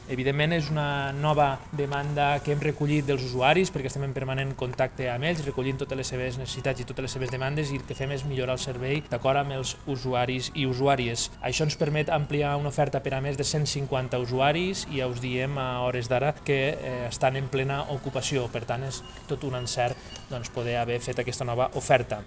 Grau ha destacat que les noves activitats ja estan totalment ocupades